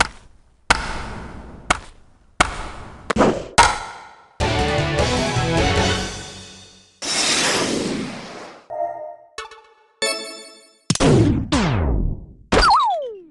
打球の音源が入ってます
• 音が違うというか、複数の音が同時に鳴っているように聞こえる。